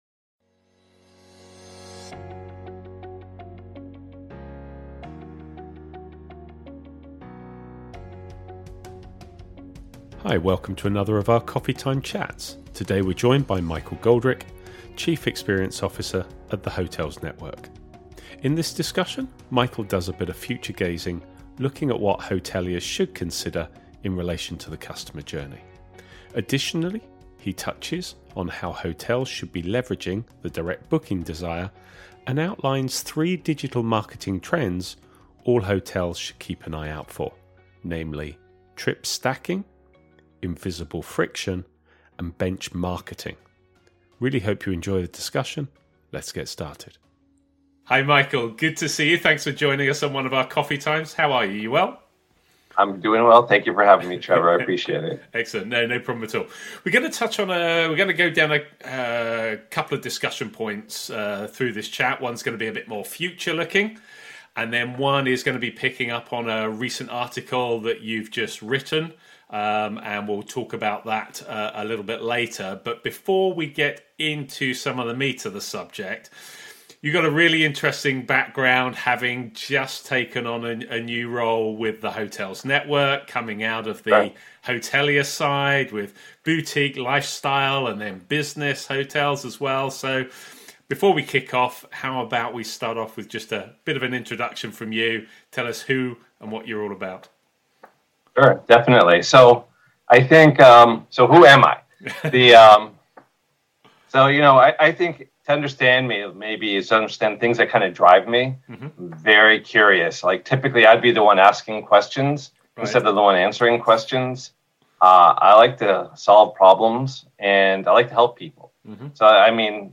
Welcome to another of our Coffee Time chats